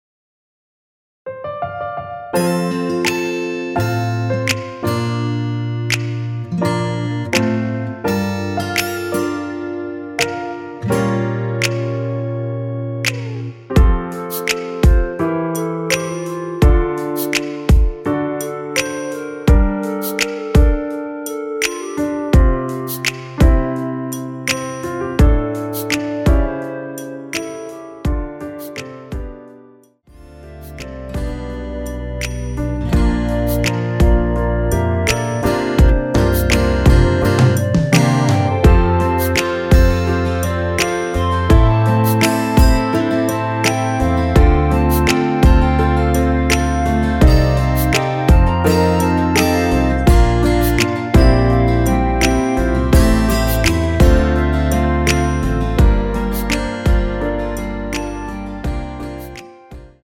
원키에서(+3)올린 멜로디 포함된 MR입니다.
멜로디 MR이라고 합니다.
앞부분30초, 뒷부분30초씩 편집해서 올려 드리고 있습니다.
중간에 음이 끈어지고 다시 나오는 이유는